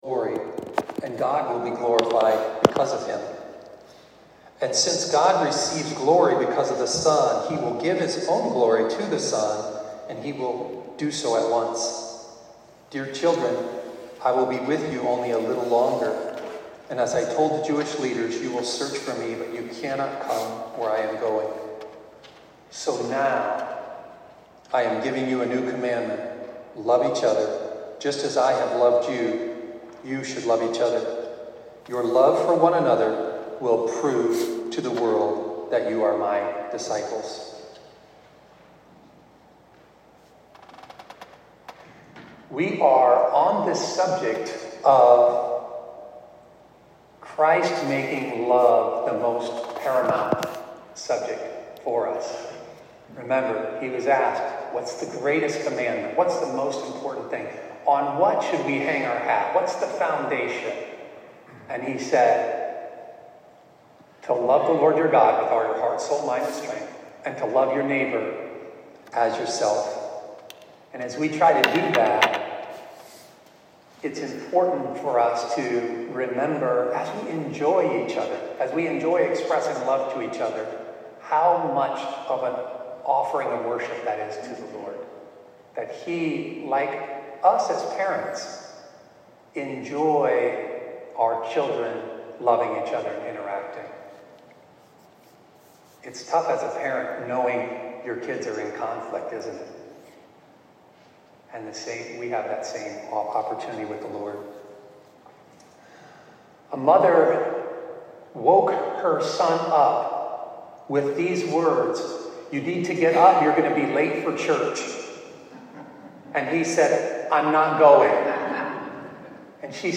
Sermon-21-Sept-25.mp3